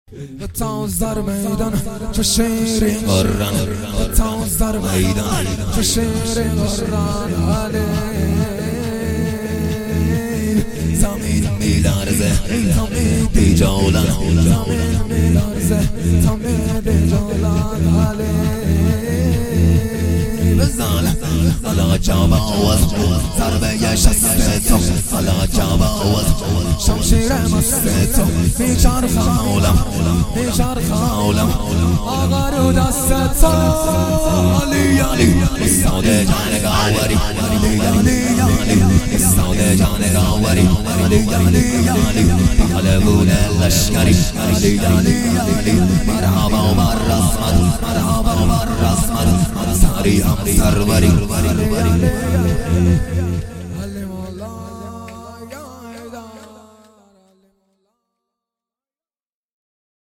شور
ولادت امام علی ۱۳۹۸